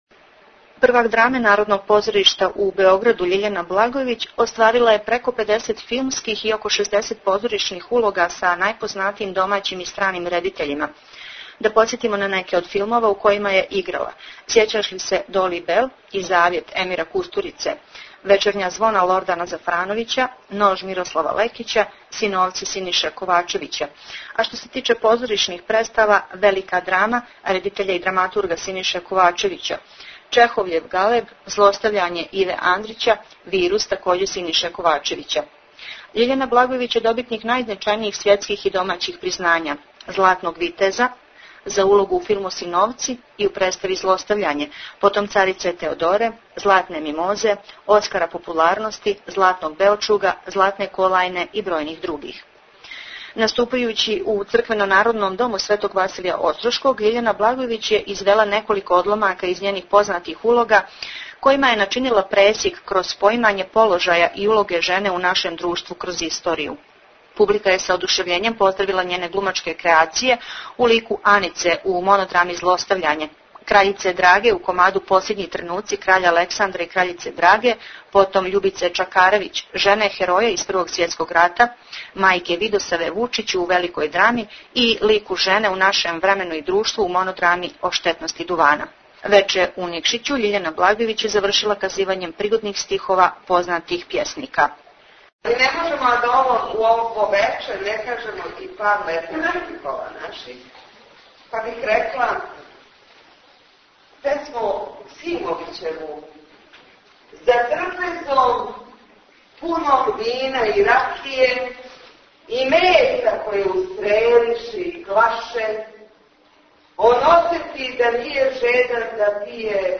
Tagged: Извјештаји
Формат: MP3 Mono 22kHz 24Kbps (CBR)